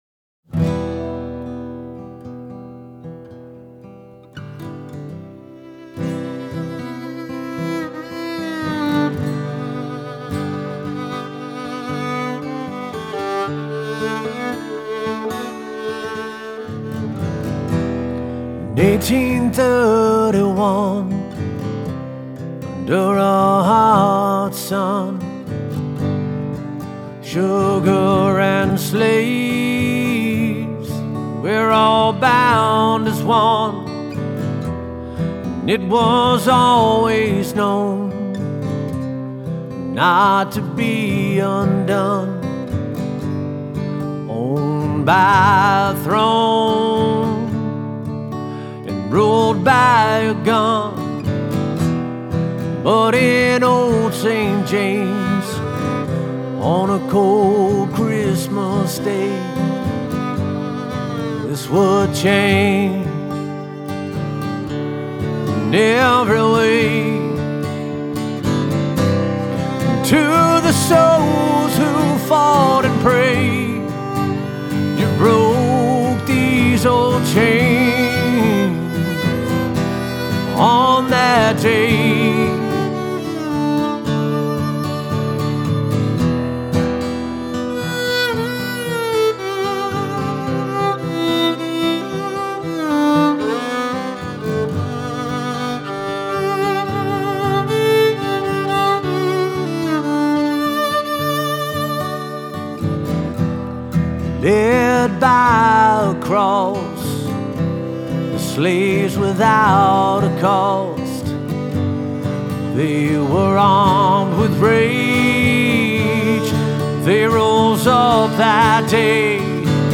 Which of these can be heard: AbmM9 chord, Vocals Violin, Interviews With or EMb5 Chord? Vocals Violin